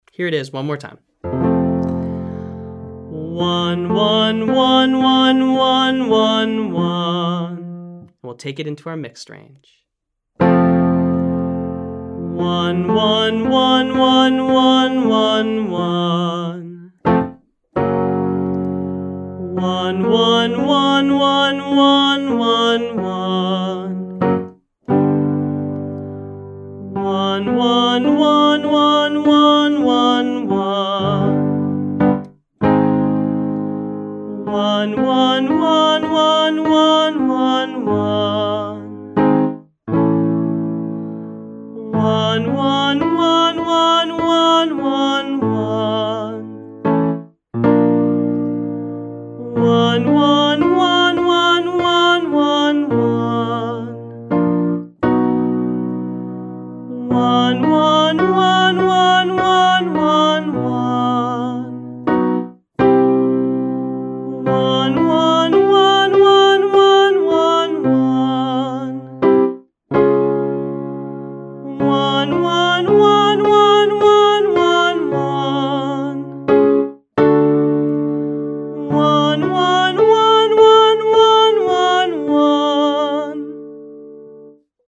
The exercise is just approximating, or getting close to, the pitch I play on the piano.